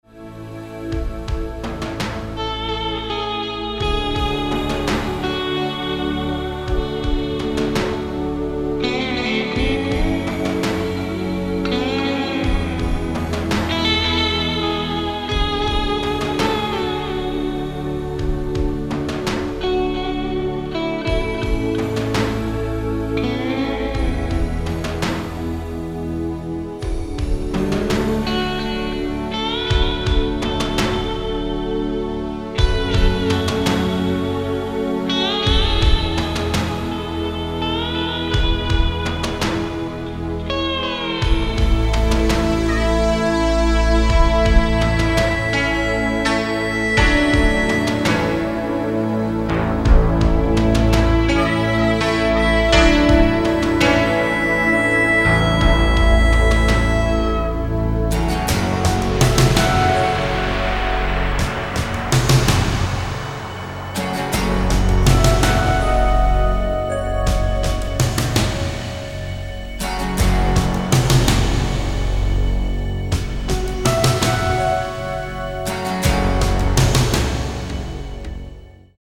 Tinged with blues and Cajun stylings